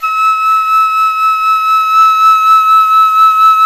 Index of /90_sSampleCDs/INIS - Opium/Partition H/DIZU FLUTE
DIZI01D#4.wav